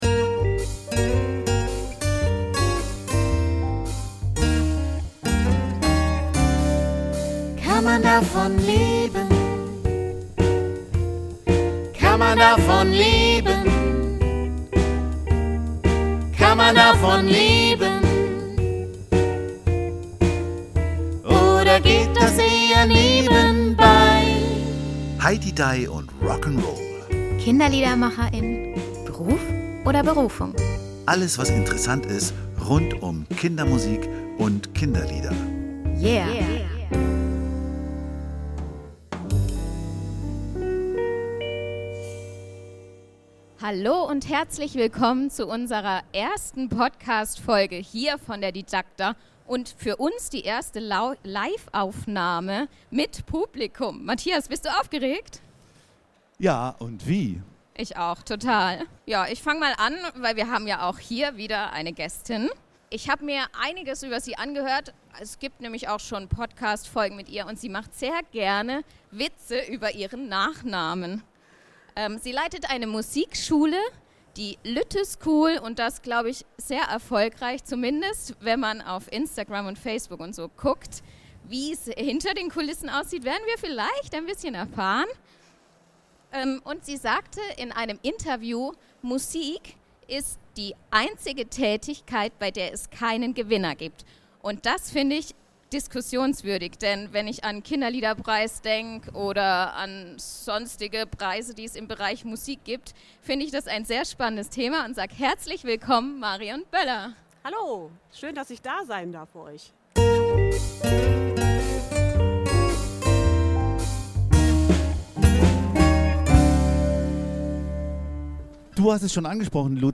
auf der Didacta in Stuttgart